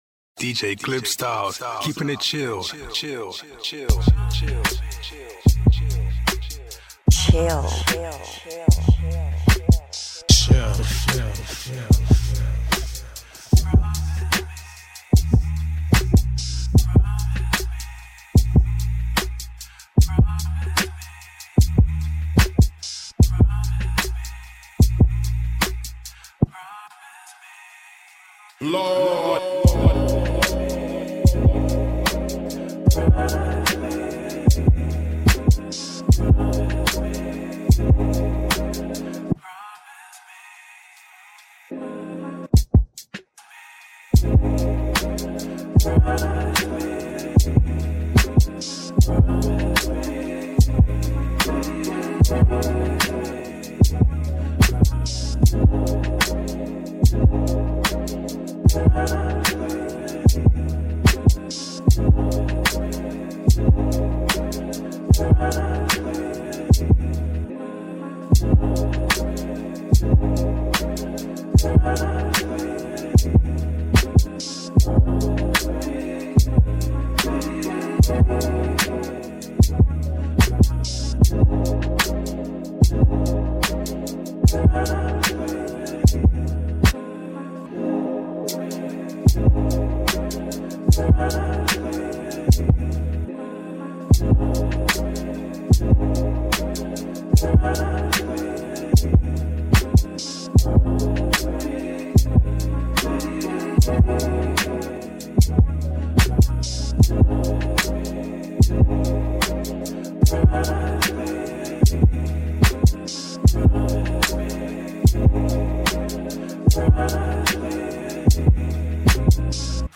Chill Beats